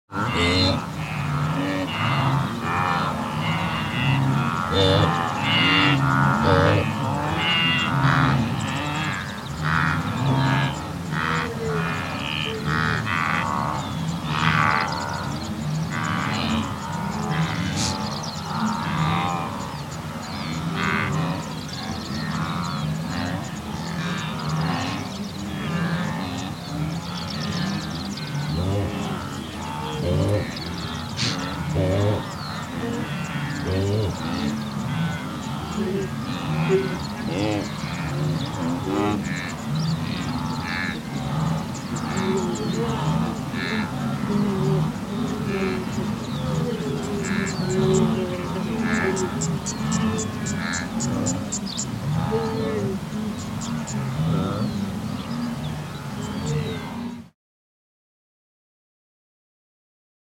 جلوه های صوتی
دانلود صدای گاو وحشی 3 از ساعد نیوز با لینک مستقیم و کیفیت بالا